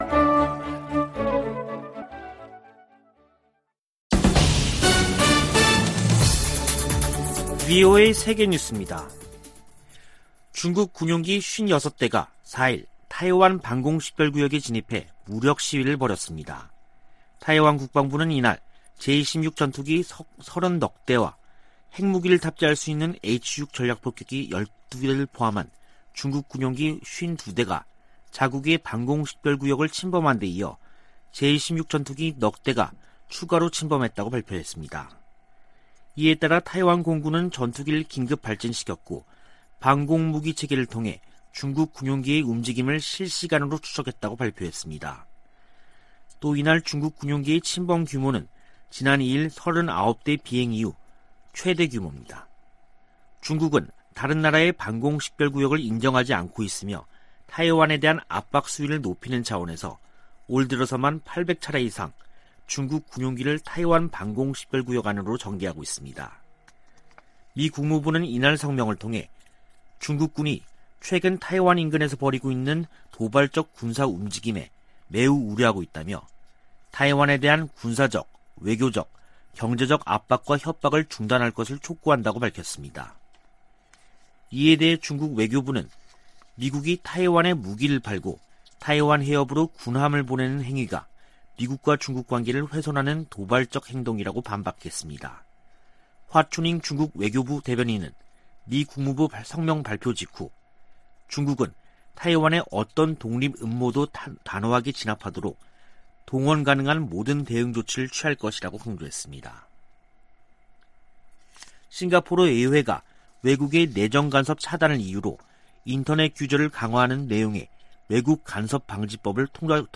VOA 한국어 간판 뉴스 프로그램 '뉴스 투데이', 2021년 10월 5일 3부 방송입니다. 유엔 안보리 전문가패널이 북한의 제재 위반 사례를 담은 보고서를 공개했습니다. 미 국무부는 북한이 안보리 긴급회의 소집을 비난한 데 대해 유엔 대북제재의 완전한 이행 필요성을 강조했습니다. 문재인 한국 대통령은 남북한 체재경쟁이나 국력 비교는 의미 없어진 지 오래라며 협력 의지를 밝혔습니다.